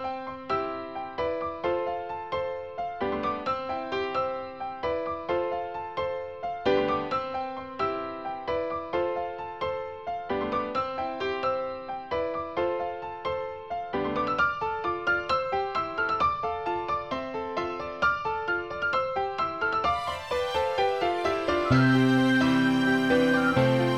no sax solo Rock 9:28 Buy £1.50